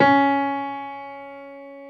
55p-pno20-C#3.wav